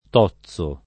tozzo [ t 0ZZ o ] s. m. («pezzo»)